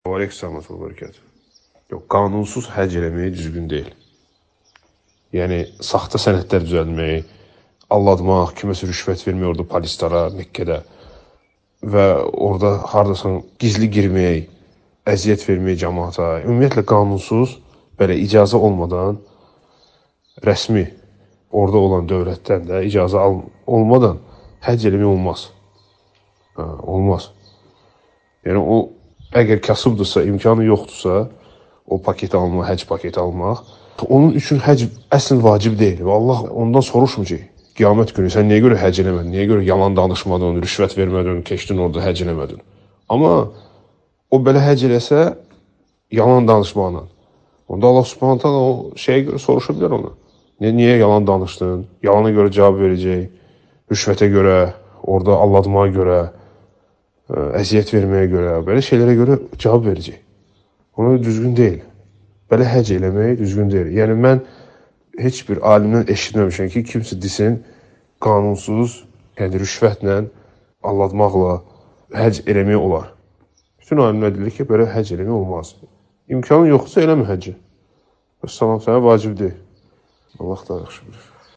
Namaz (SUAL-CAVAB)